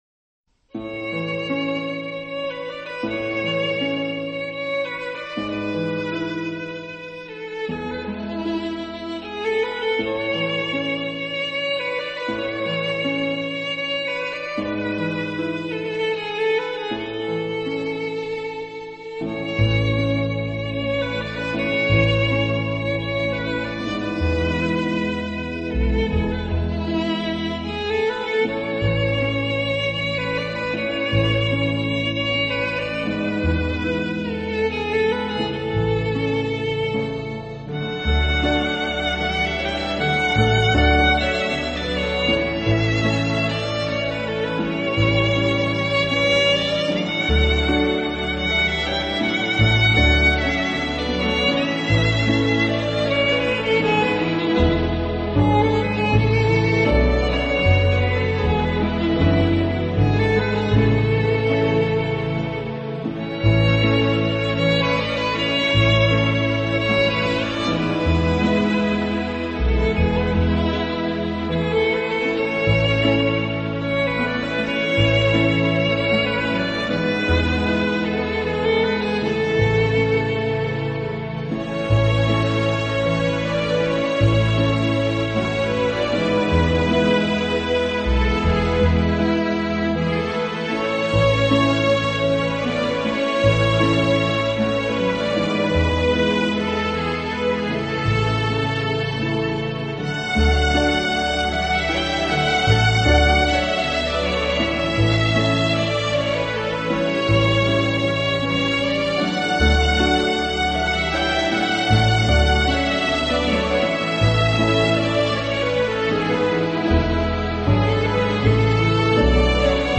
音乐类型：SoundTrack 原声 音乐风格：Original Score,Film Music